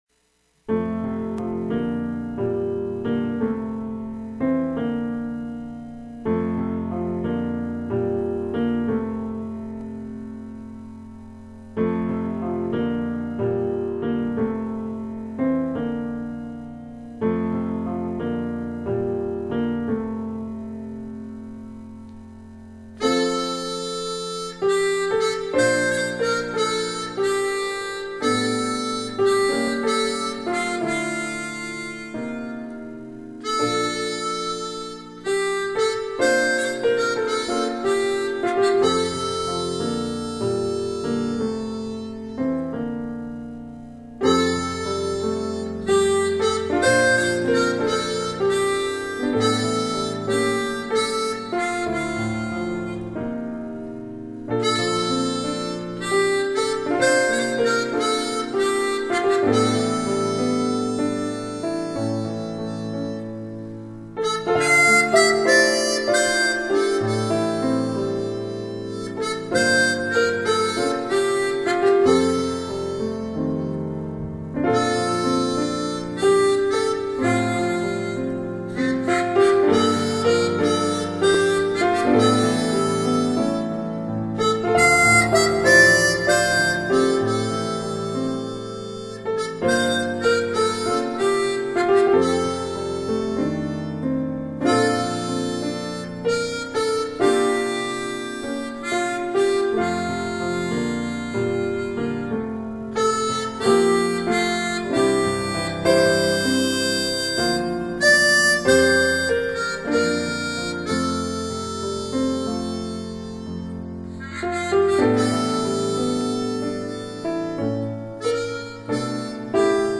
하모니카 연주입니다